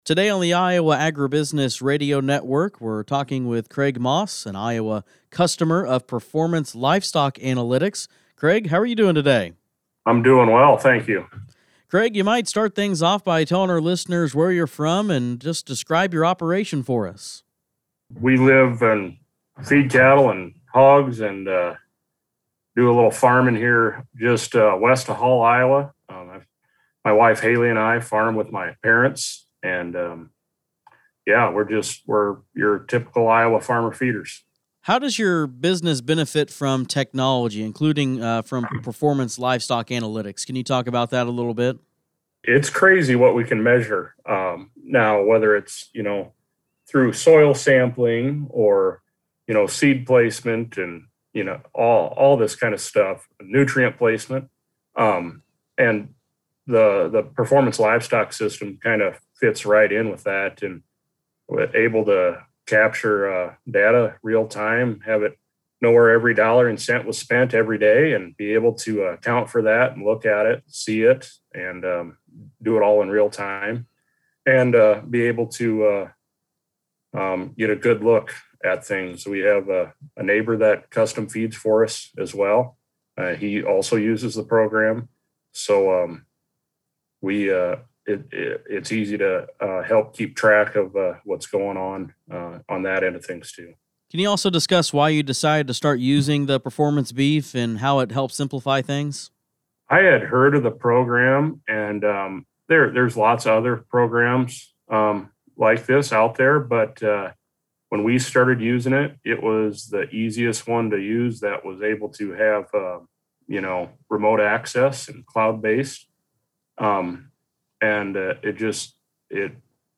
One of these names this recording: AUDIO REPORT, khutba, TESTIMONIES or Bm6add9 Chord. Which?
AUDIO REPORT